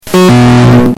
discord distorted
discord_distorted.mp3